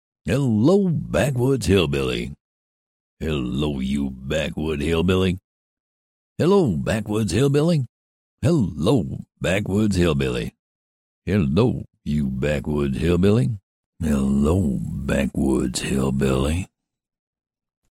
金丝雀" ~'
描述：简单的金丝雀鸣叫有一些背景噪音
标签： 金丝雀 唧唧 吹口哨
声道立体声